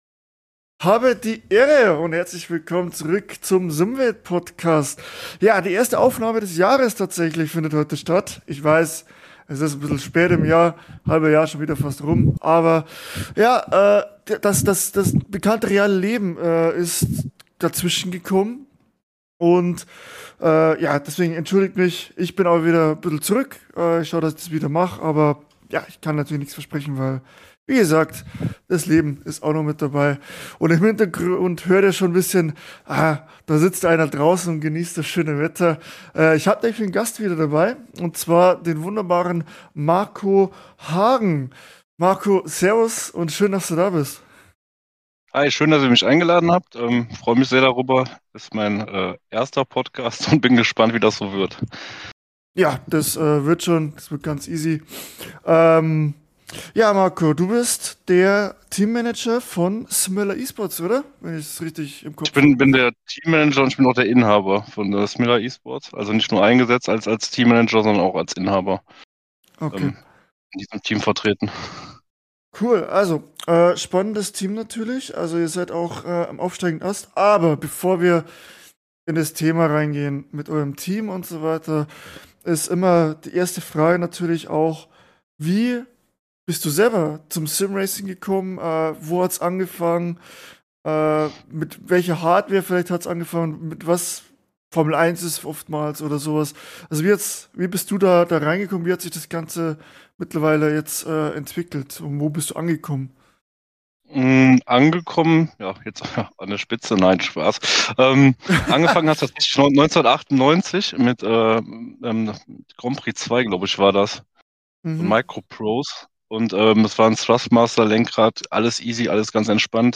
Folge 65: Top-Team im Gespräch ~ Simwelt-Podcast Podcast